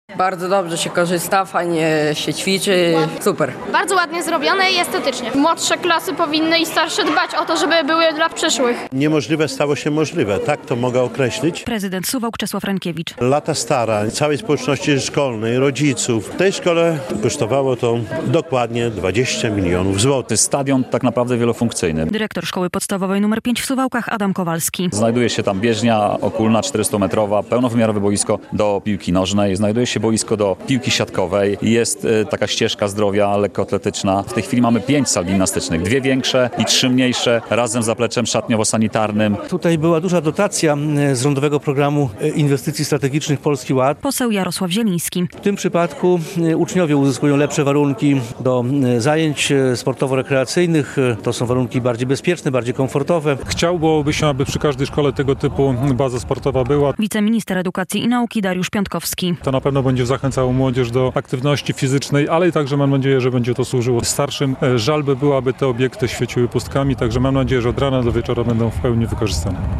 W Suwałkach otwarto jeden z największych w województwie kompleks sportowy - relacja